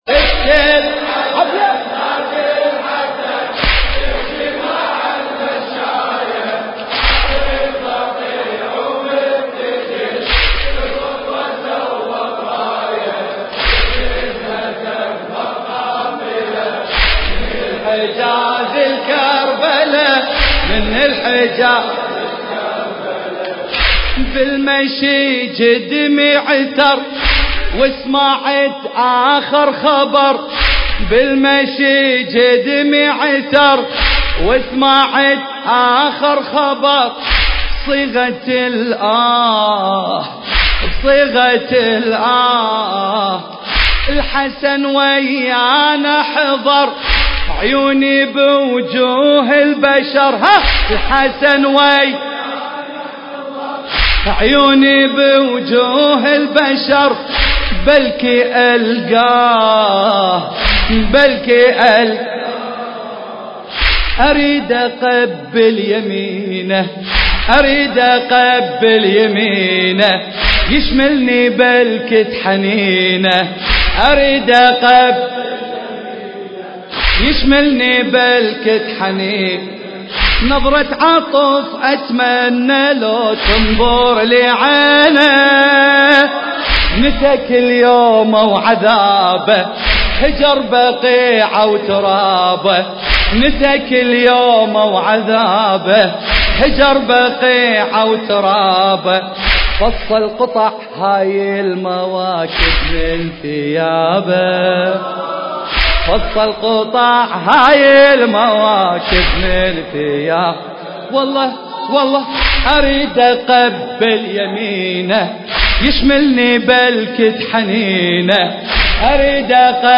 المراثي
الحجم: 3.91 MB الشاعر: حمزة السماوي المكان: حسينية وقصر الزهراء عليها السلام/ هولندا- اوترخت